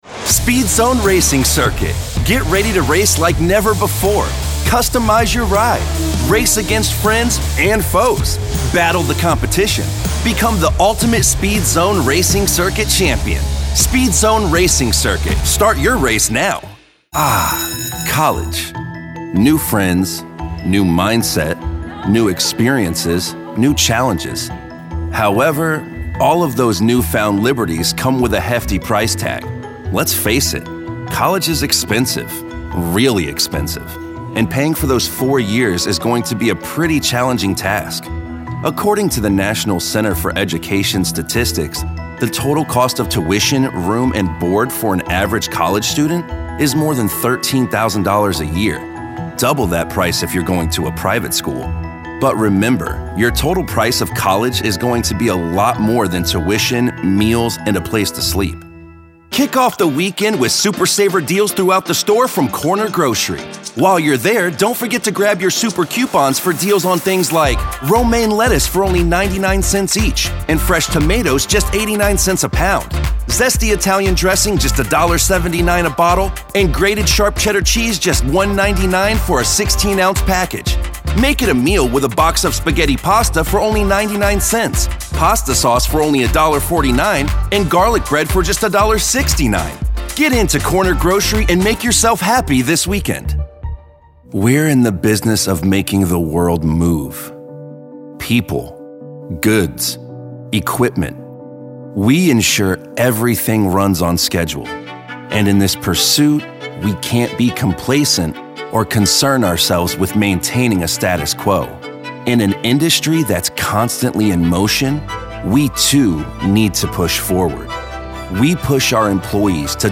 announcer, anti-announcer, caring, concerned, confessional, confident, conversational, friendly, genuine, guy-next-door, high-energy, informative, inspirational, Matter of Fact, millennial, perky, promo, retail, sincere, thoughtful, upbeat, warm